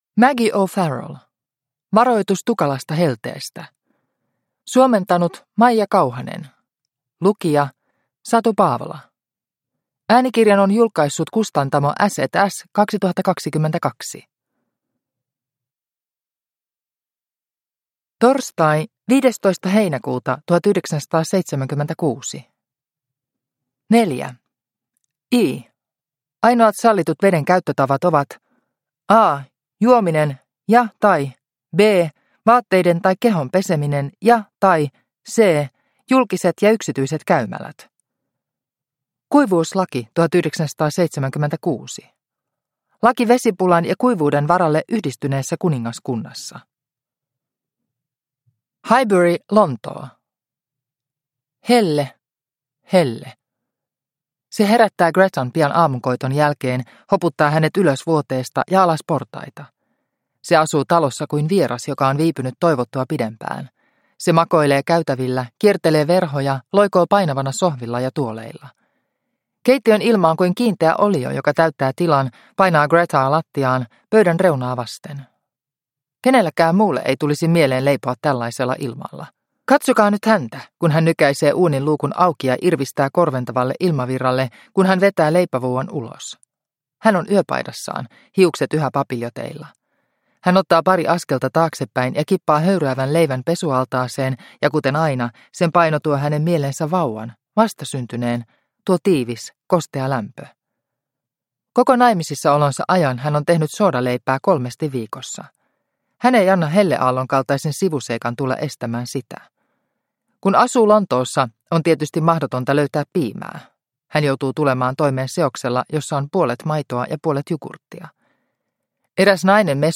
Varoitus tukalasta helteestä – Ljudbok – Laddas ner